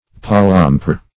Search Result for " palampore" : The Collaborative International Dictionary of English v.0.48: Palampore \Pal`am*pore"\, n. See Palempore .